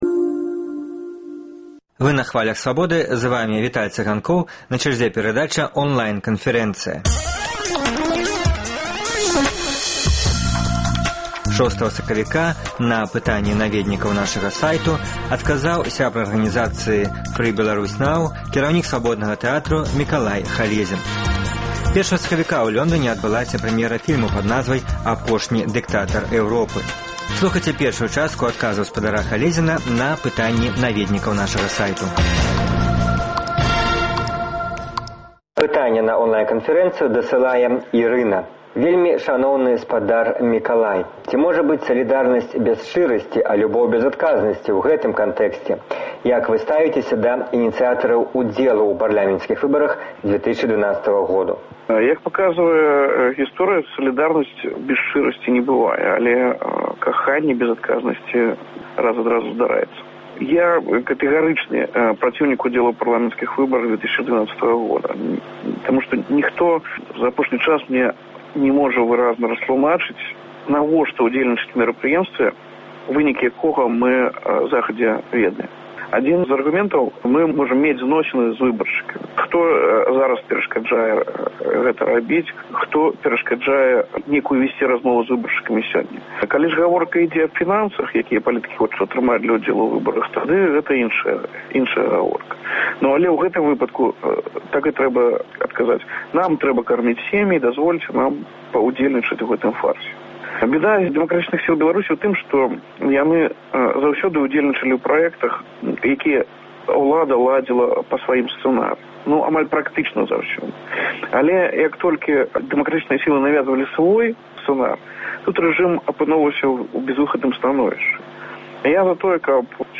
Онлайн- канфэрэнцыя